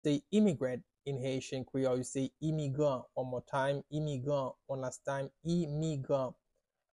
“Immigrant” in Haitian Creole – “Imigran” pronunciation by a native Haitian Creole tutor
“Imigran” Pronunciation in Haitian Creole by a native Haitian can be heard in the audio here or in the video below:
How-to-say-Immigrant-in-Haitian-Creole-–-Imigran-pronunciation-by-a-native-Haitian-Creole-tutor.mp3